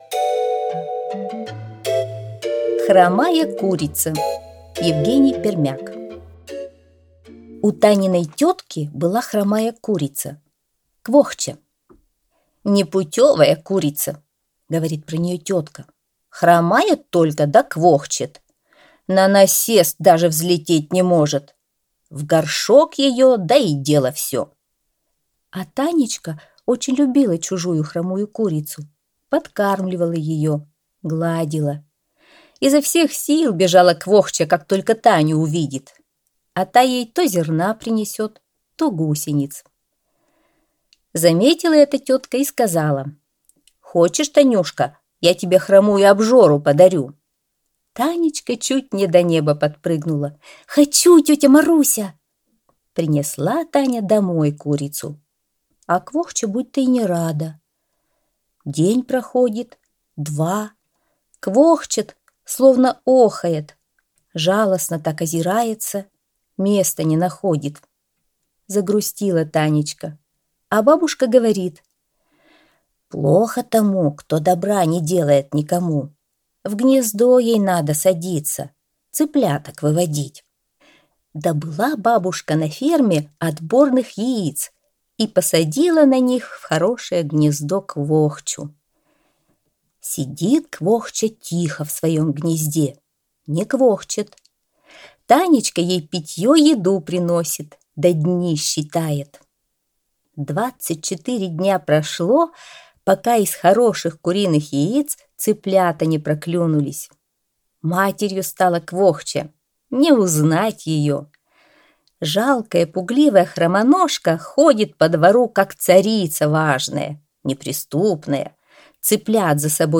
Аудиорассказ «Хромая курица»